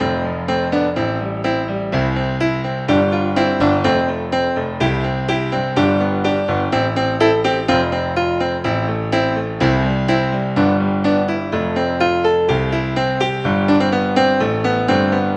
标签： 125 bpm Chill Out Loops Brass Loops 2.81 MB wav Key : A
声道立体声